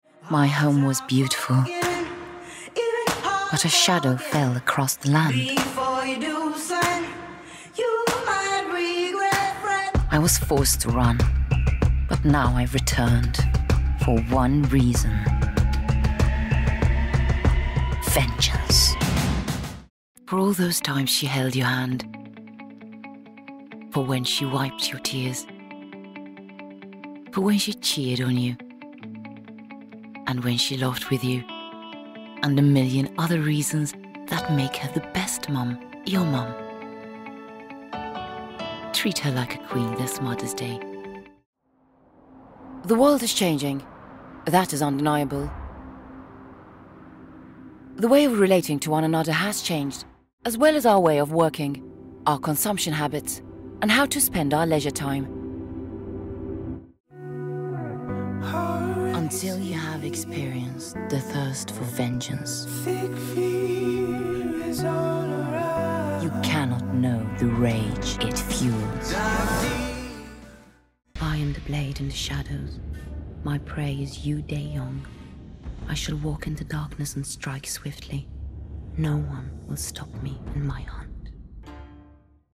This is a warm, trustworthy, "guy next door" style and a clearly-enunciated narrator voice which ensures reliable communication and interpretation of the message within your voice cast....
English (North American) Adult (30-50) | Older Sound (50+)
1202AG_Gravitas_Voice_Reel.mp3